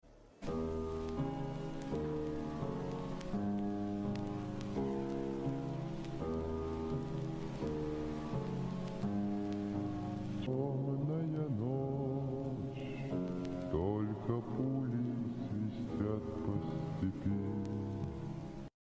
The doomsday planescary#avation sound effects free download